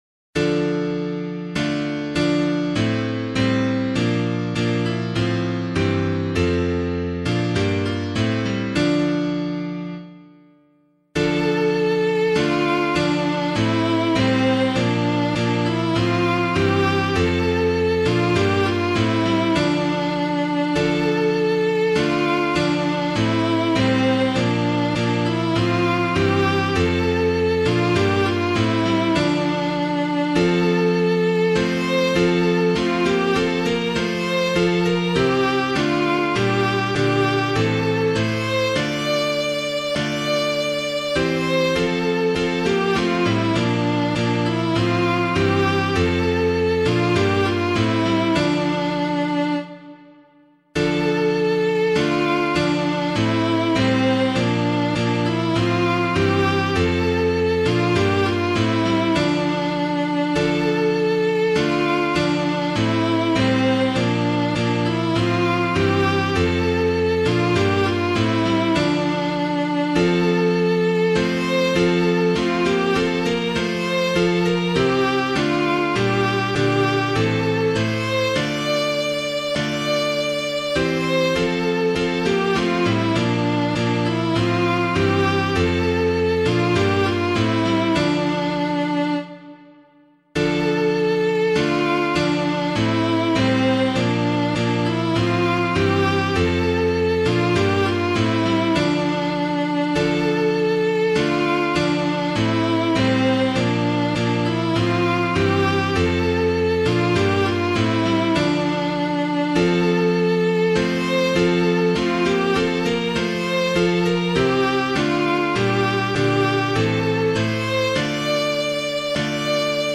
piano
By Faith Our Father Abraham [Joncas - SALVATION] - piano.mp3